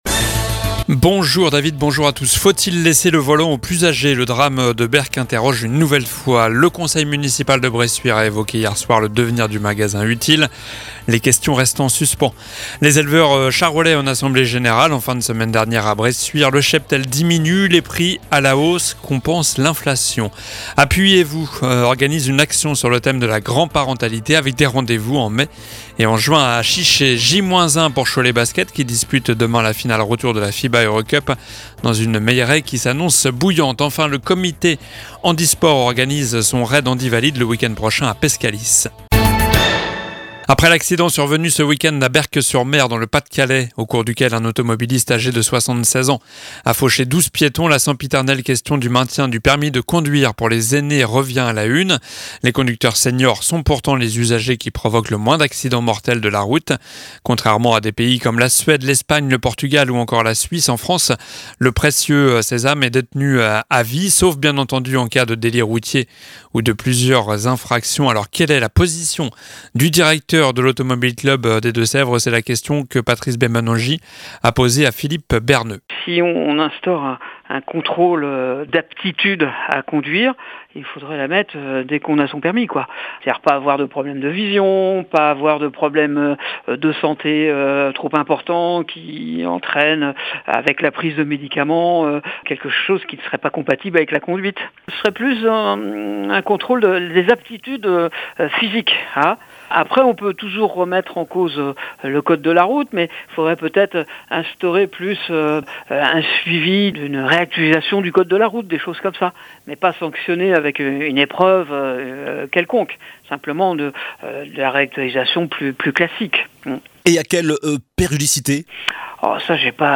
Journal du mardi 25 avril (midi)